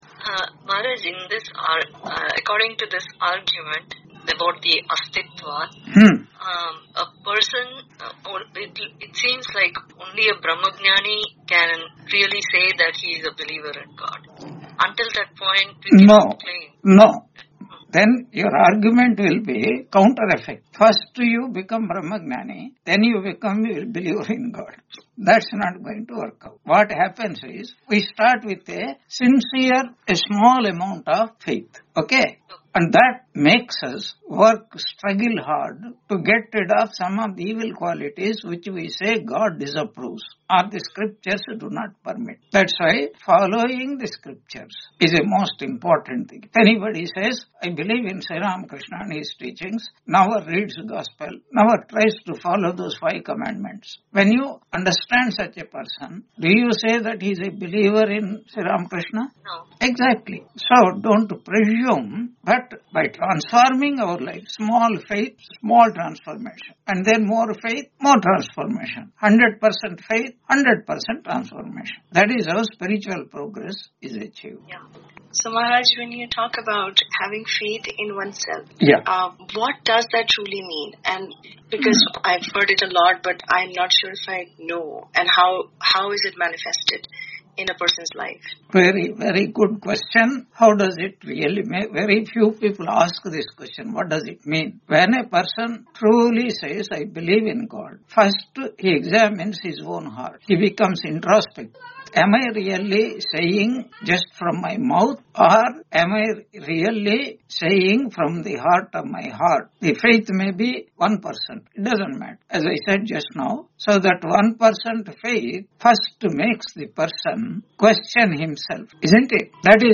Taittiriya Upanishad Lecture 80 Ch2 6.1-3 on 26 November 2025 Q&A - Wiki Vedanta